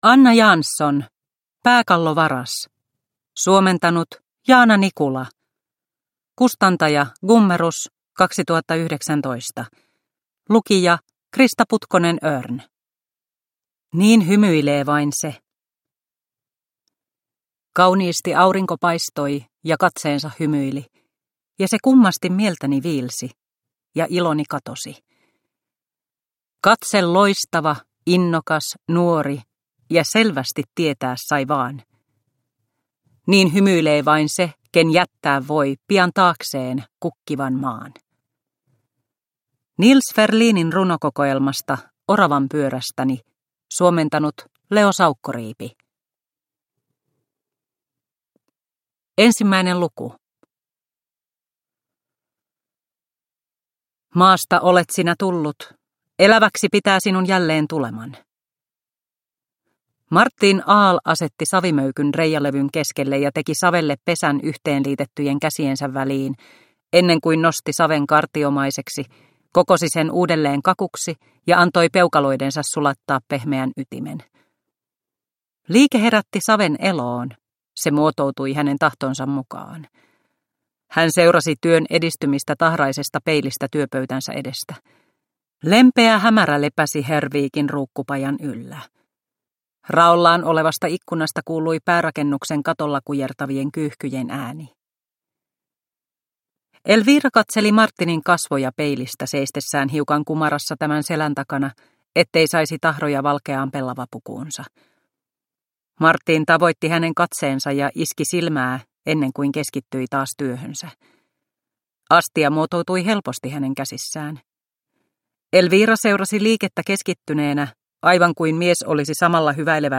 Pääkallovaras – Ljudbok – Laddas ner